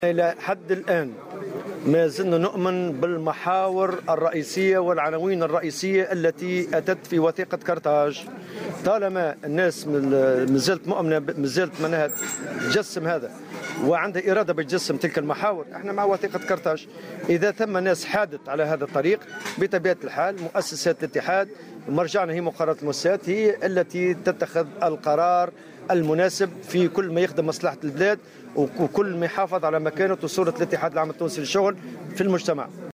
وأضاف في تصريح لمراسلة "الجوهرة أف أم" على هامش انعقاد الجامعة العامة للقيمين و القيمين العامين بالحمامات، أن الاتحاد سيتخذ الاجراءات والقرارات المناسبة في صورة الحياد عن هذه المحاور.